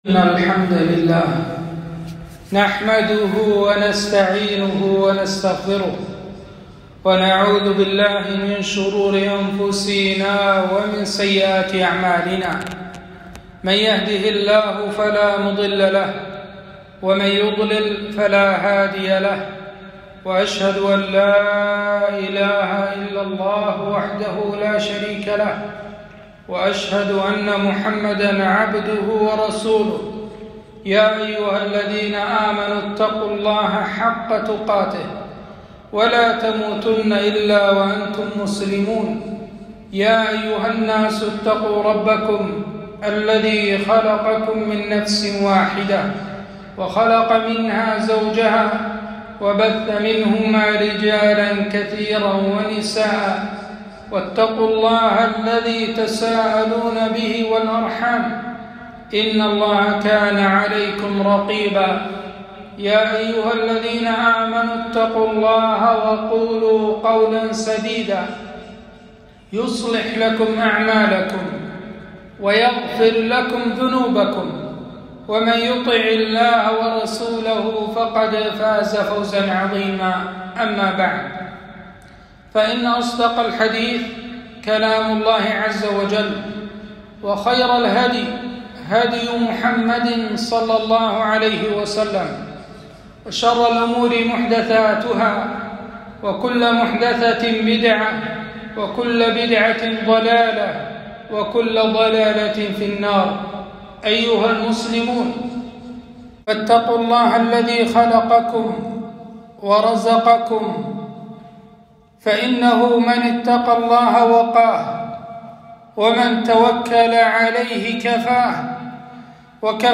خطبة - القناعة والرضا